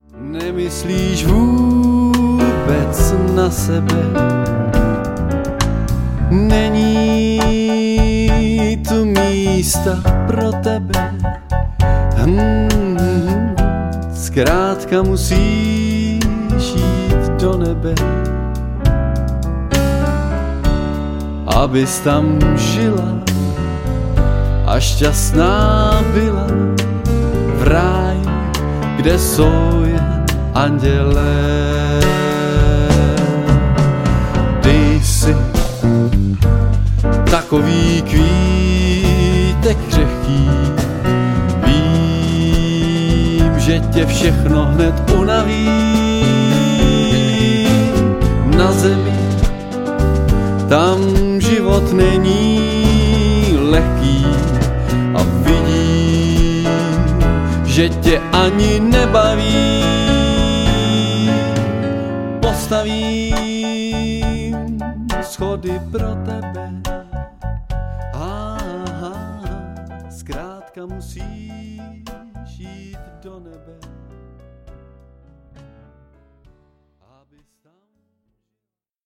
České a světové evergreeny